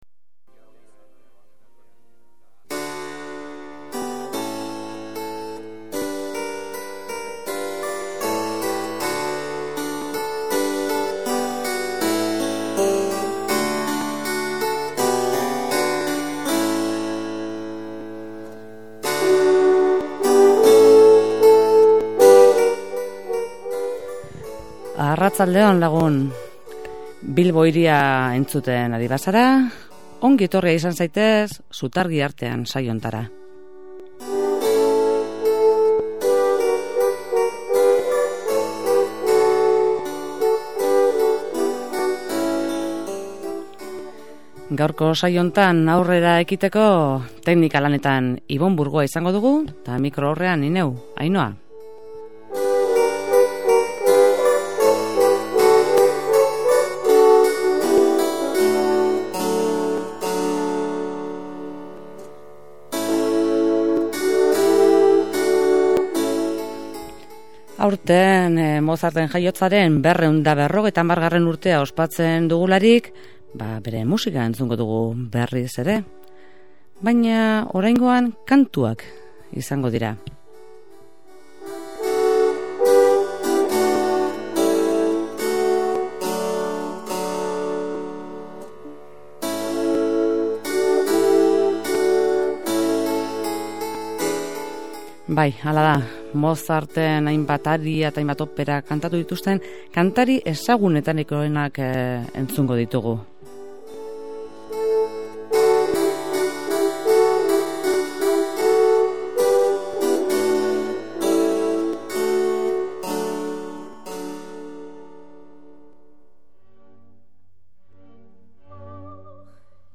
operetako aria ezberdinak
sopranoa
tenorea
baxua